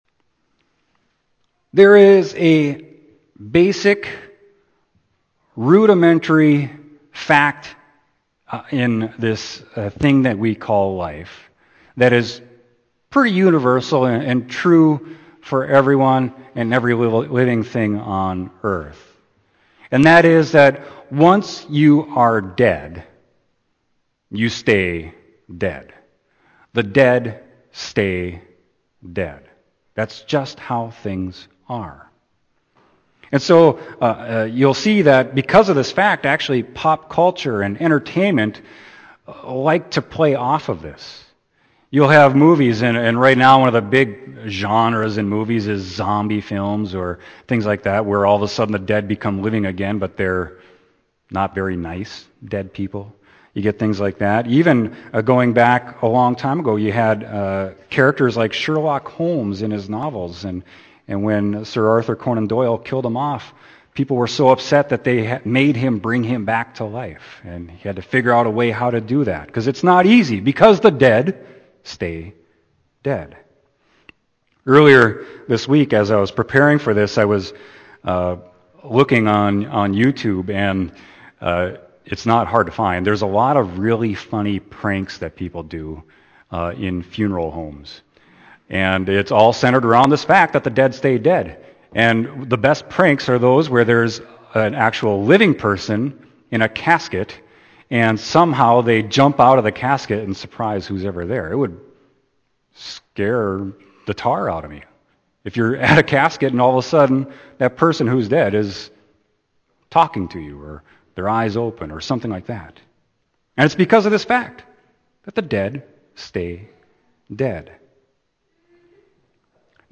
Sermon: John 11.17-27; 38-44